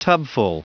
Prononciation du mot tubful en anglais (fichier audio)
Prononciation du mot : tubful